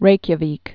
(rākyə-vēk, -vĭk)